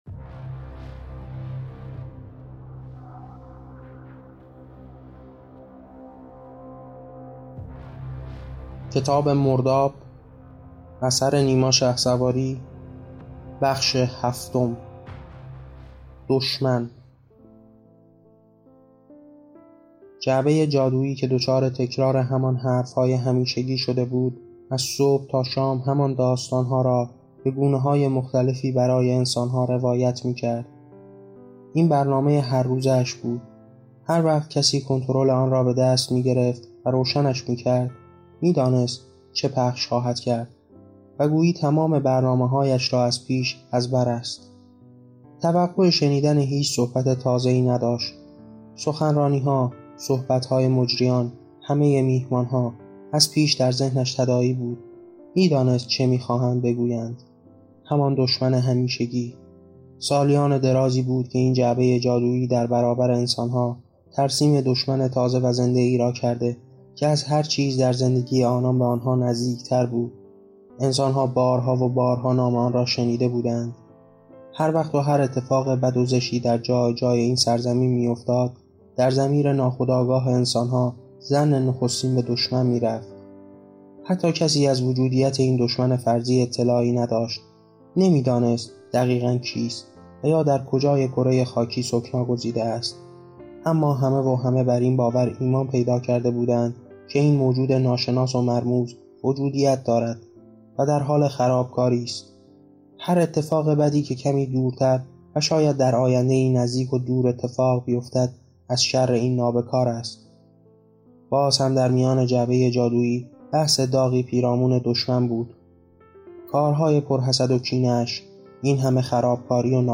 کتاب صوتی مرداب - بخش هفتم قسمت پایانی